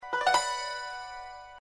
ملودی هشدار پیامک